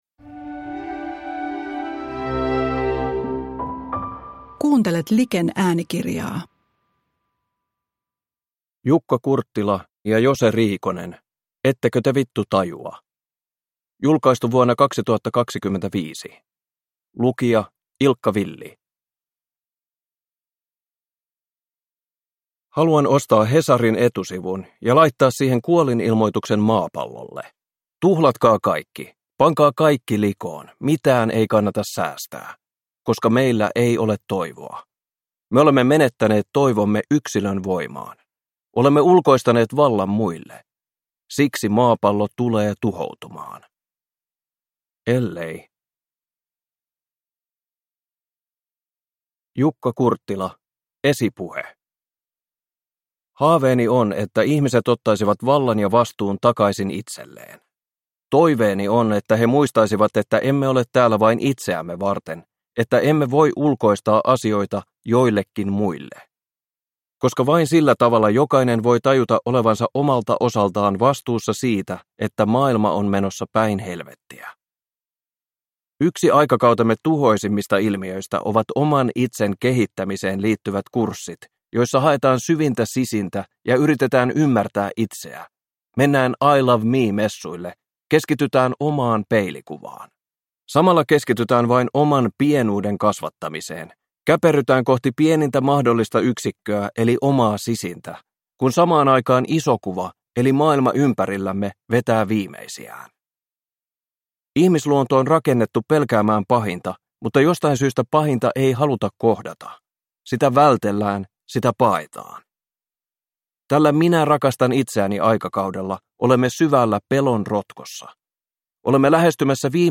Ettekö te v*ttu tajua (ljudbok